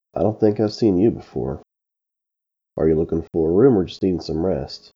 Average Male